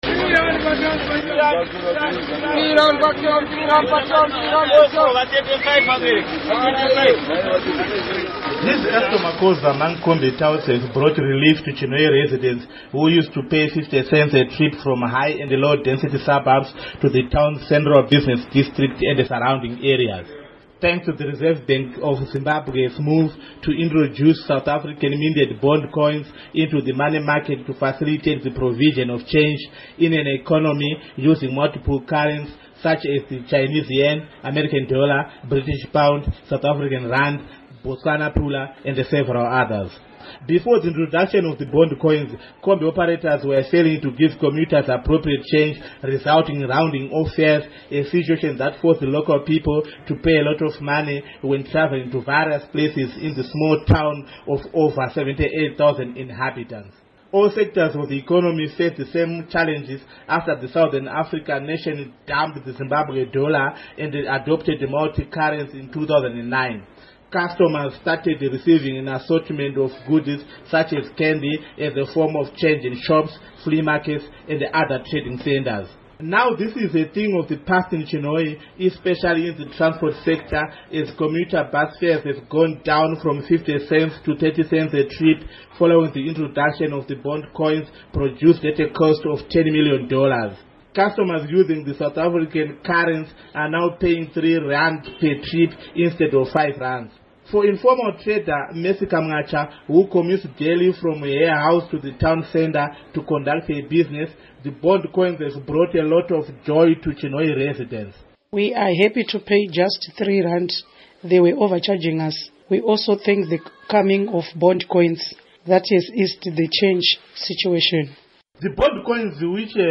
Report on Bond Coins